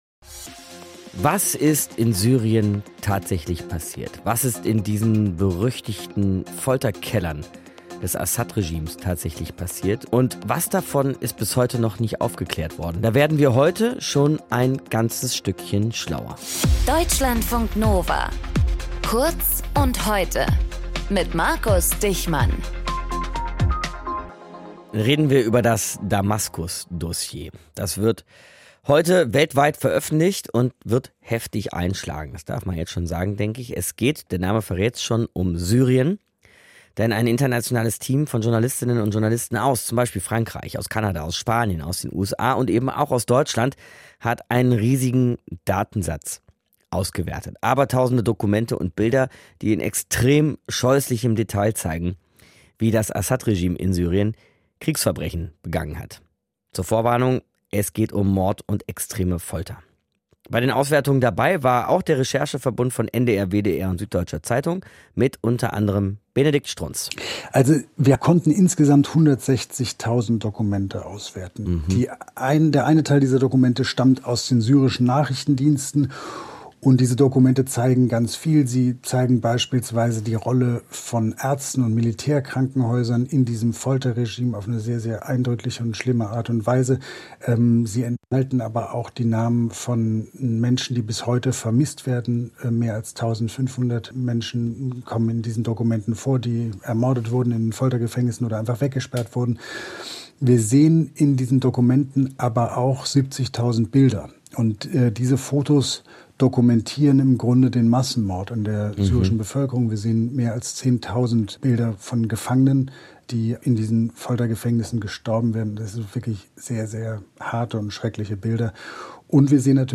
Moderator:
Gesprächspartner: